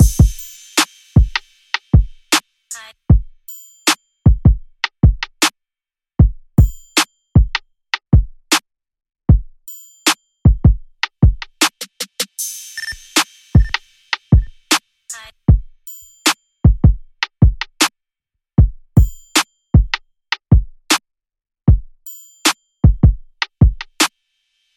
陷阱鼓循环播放02 没有帽子
描述：一个155 BPM的陷阱鼓循环，没有hihats和808。
Tag: 155 bpm Trap Loops Drum Loops 4.17 MB wav Key : Unknown